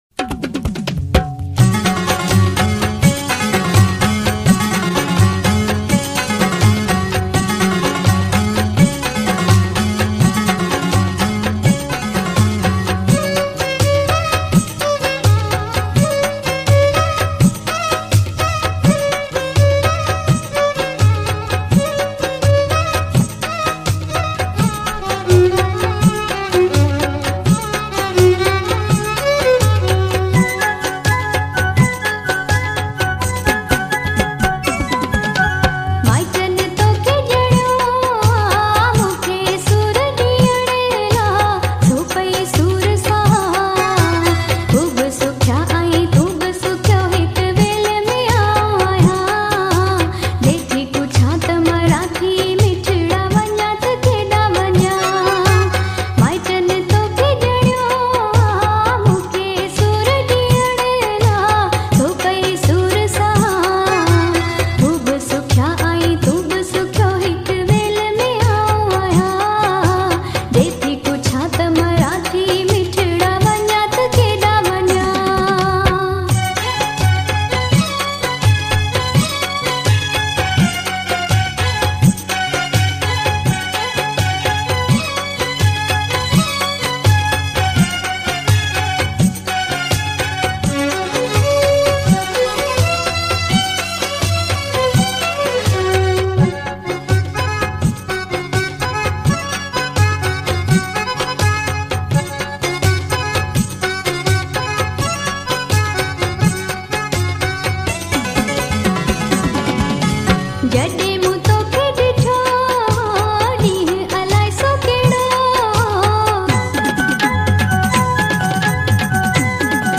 Old Sindhi Hits Song